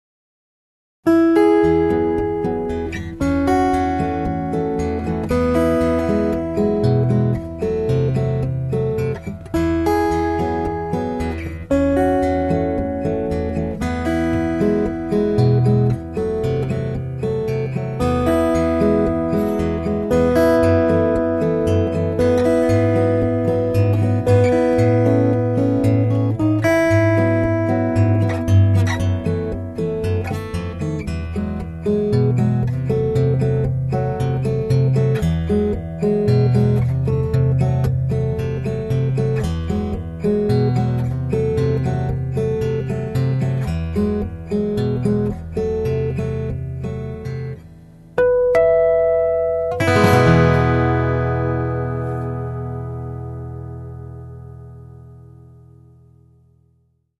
音質はいままで使っていたBOSS AD-5よりもナチュラルな感じ。自然な音です。
こんな感じの音になりました。レアアース・ブレンドのマグネットとコンデンサーのミックス具合は50:50です。
Northwood R-70-OMの音が一番自然に聴こえる気がします。
LR-Northwood.mp3